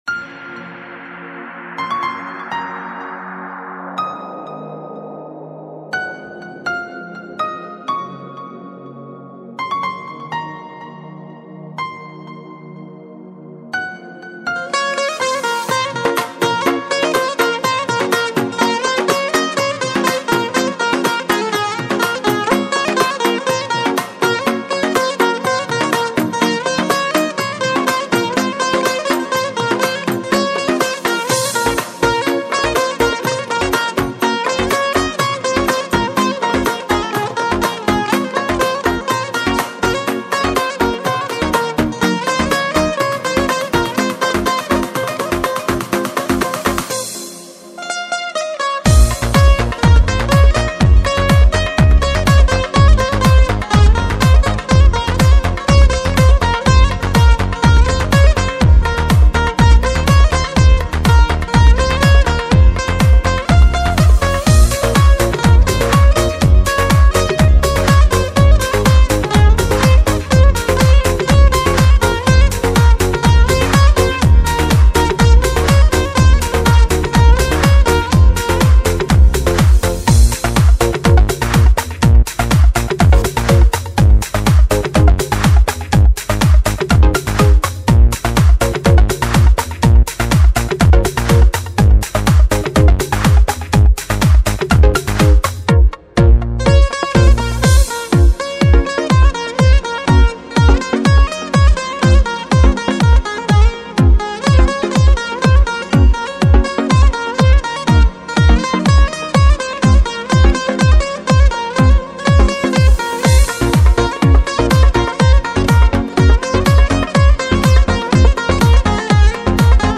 lyubimaya_vostochnaya_muzyka_dlya_tebya.mp3